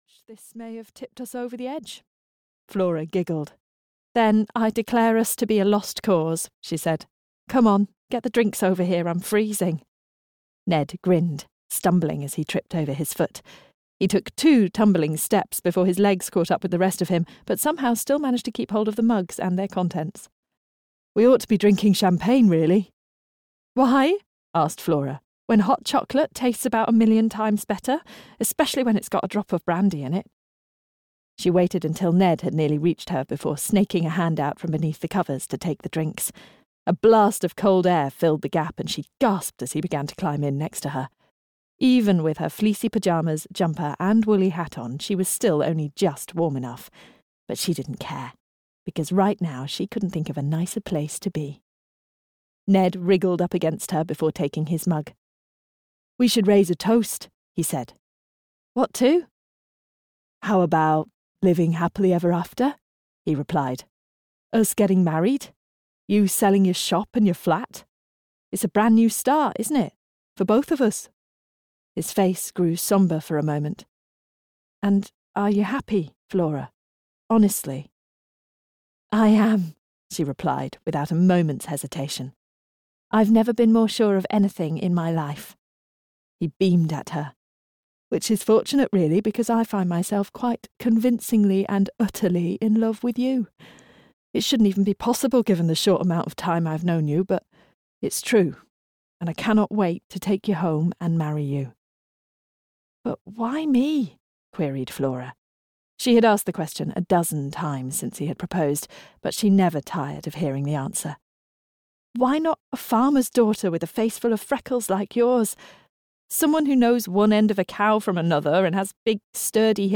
The House at Hope Corner (EN) audiokniha
Ukázka z knihy